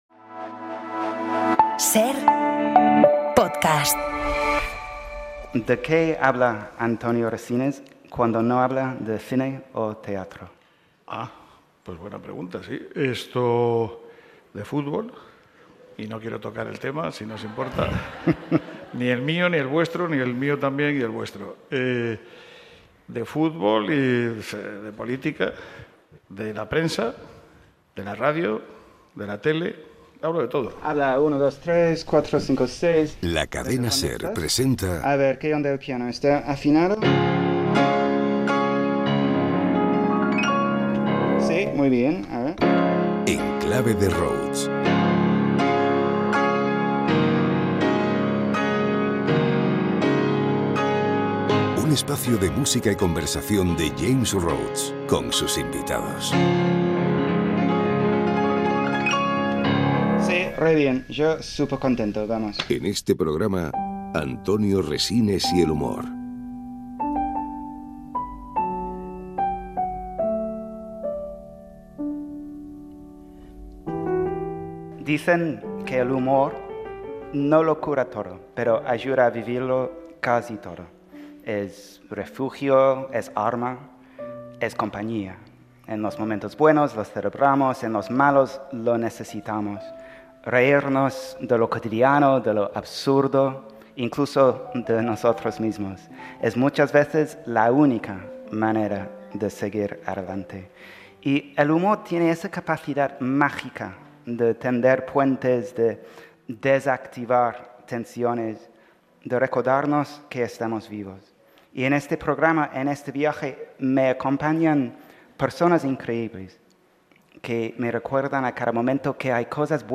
James Rhodes entrevista a Antonio Resines en el auditorio del Centro Botín en Santander. El pianista y el actor charlan sobre el humor, sobre la vida y sobre cómo estos dos aspectos se combinan, hasta en las situaciones más complicadas